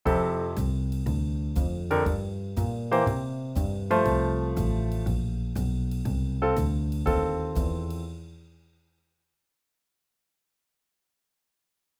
4ビートを弾くときのポイント
曲4ビート.wav